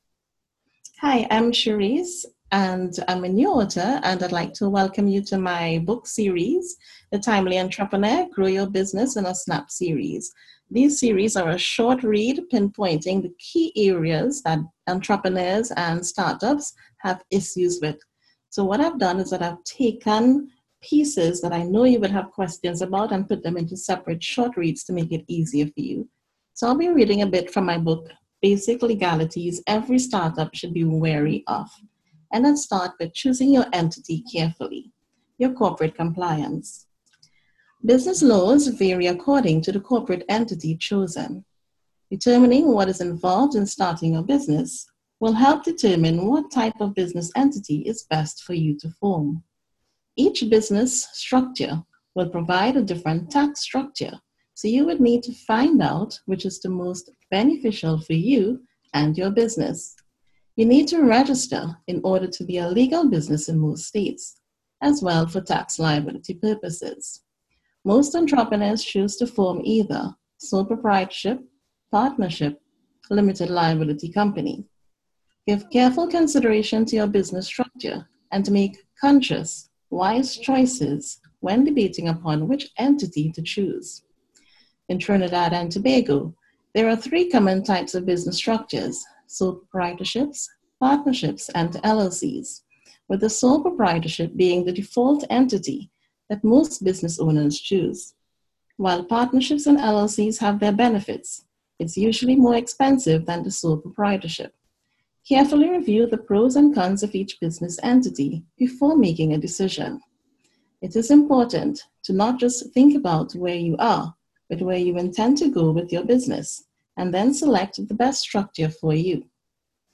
An excerpt reading